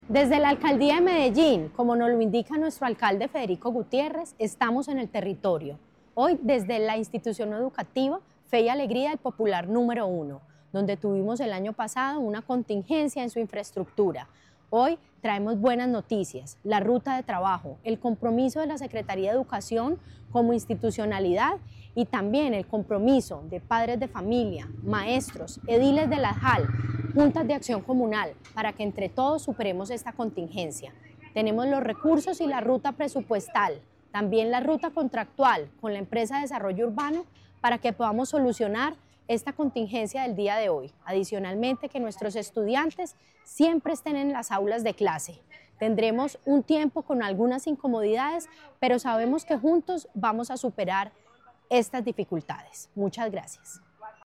Declaraciones-secretaria-de-Educacion-Carolina-Franco-Giraldo-1.mp3